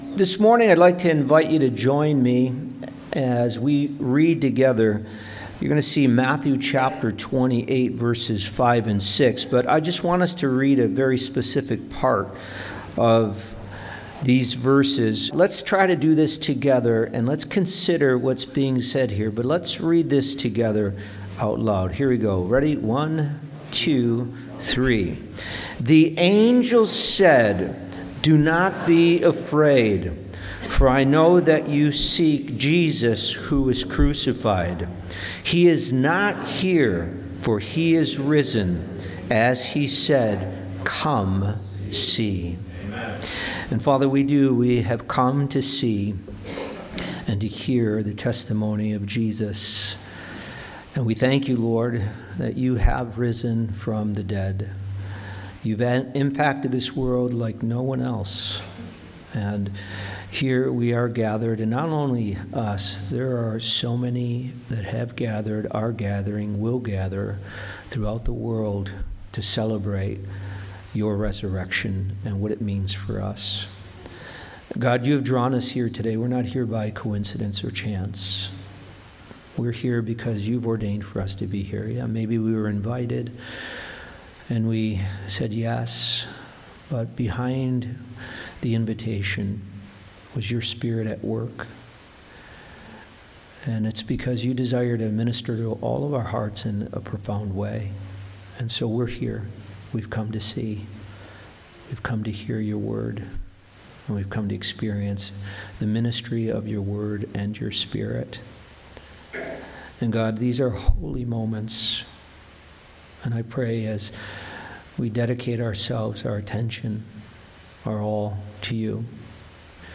From Series: "Topical Message"